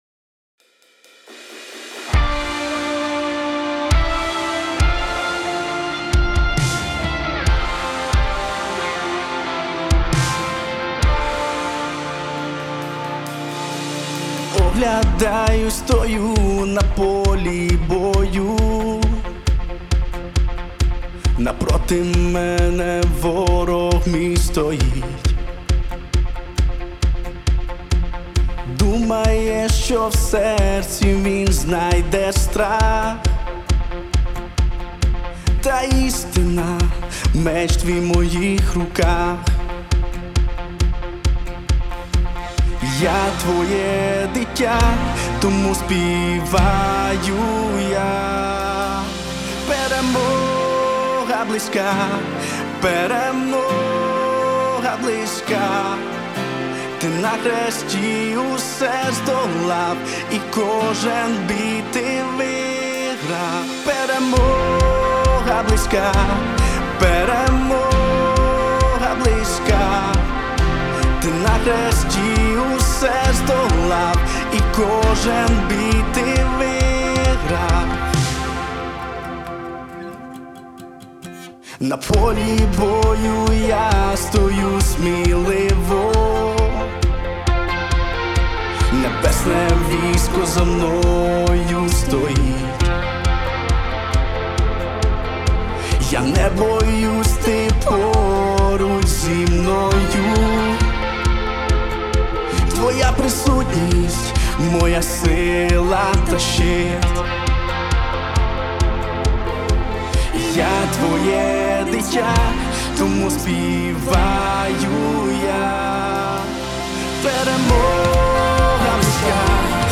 38 просмотров 113 прослушиваний 7 скачиваний BPM: 135